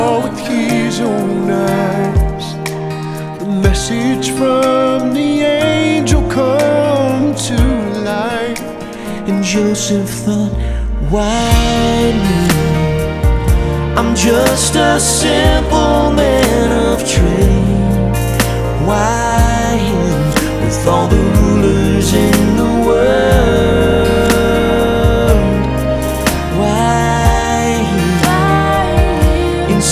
• Christian